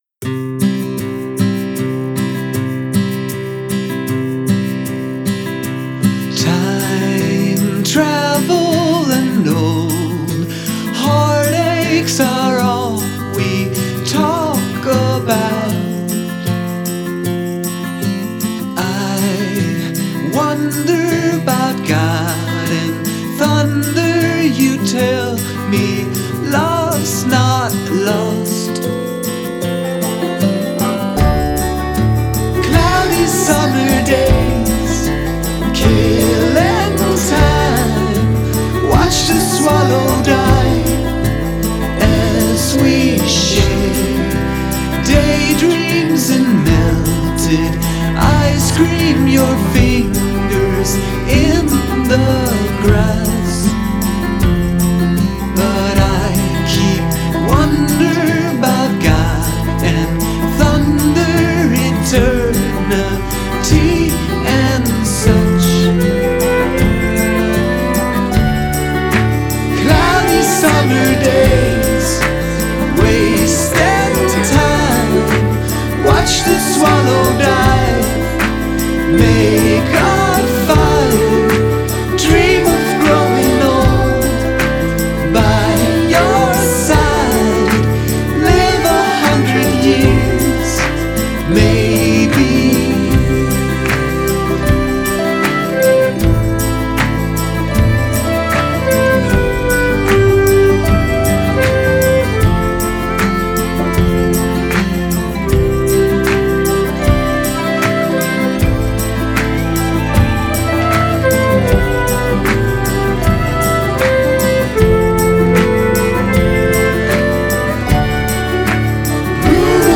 anchored by gently plucked banjo and metaphysical queries.
the gentle female backing vox